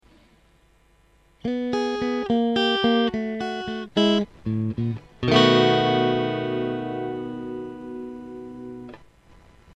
The notes then move chromatically down to a G# and E which is are also note from the E chord.
E Blues Intro 6th Lick